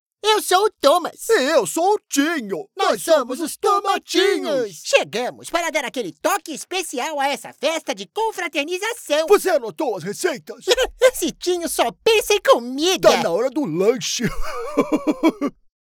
Animación
Mi tono es conversacional, pero también puedo hacer la voz de un adulto joven y de una persona mayor.